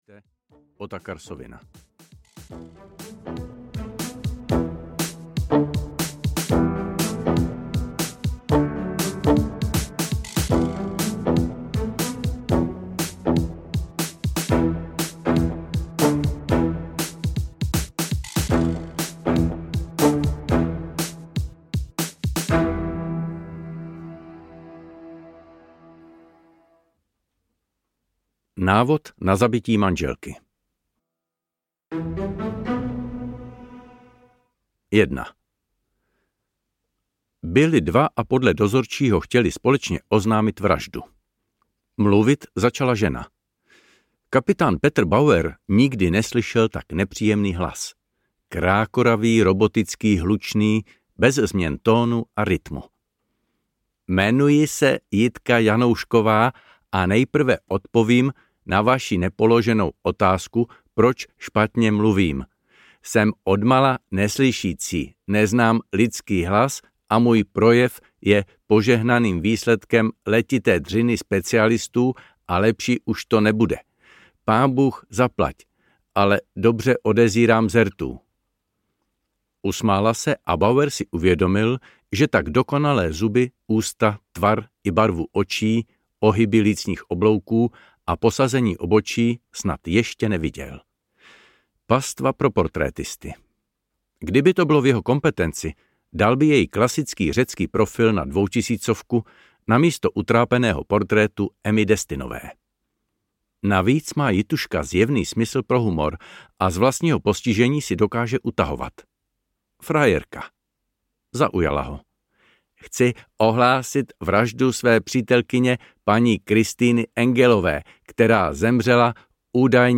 Vymazlené vraždy 2 audiokniha
Ukázka z knihy